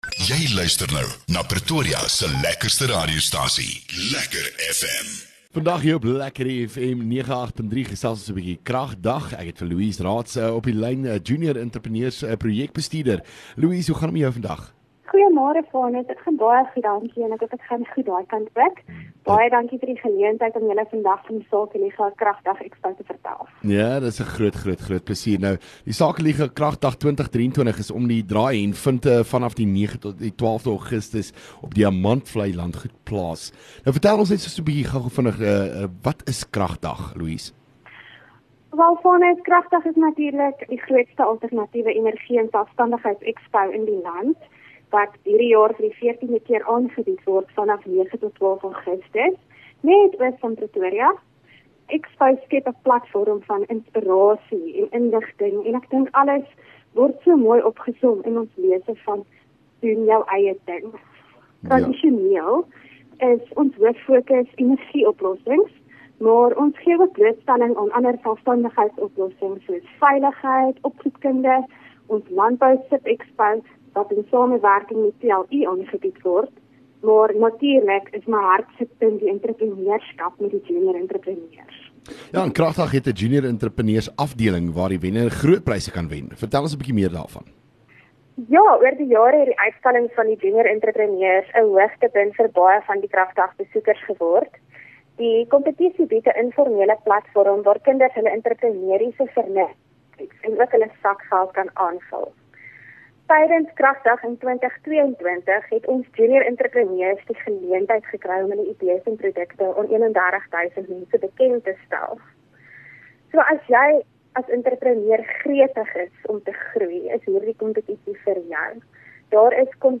LEKKER FM | Onderhoude